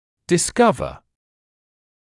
[dɪ’skʌvə][ди’скавэ]обнаруживать; узнавать